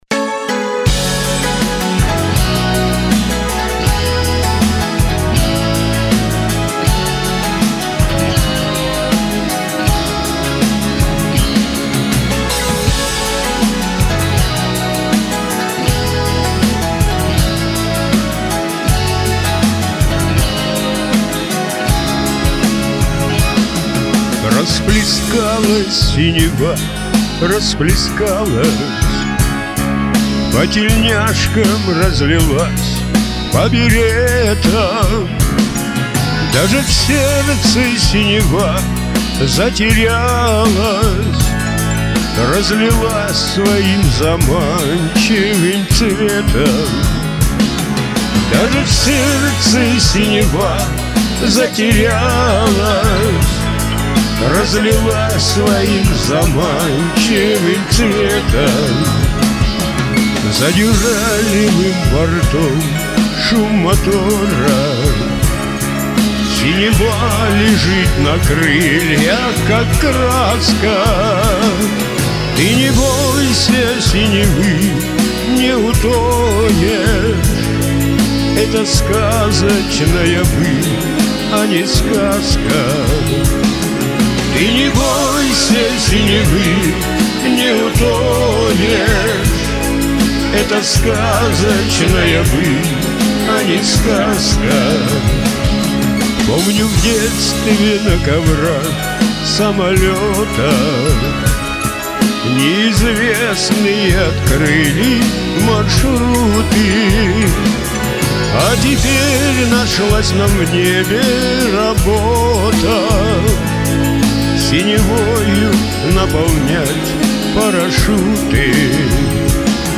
У первого пафосно, у второго по-кабацки.